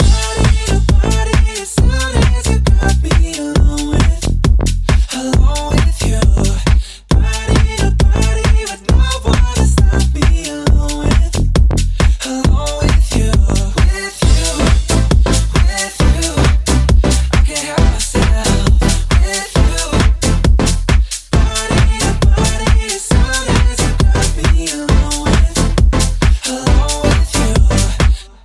deep house
Genere: house, deep house, remix